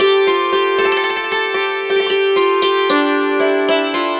Dulcimer19_114_G.wav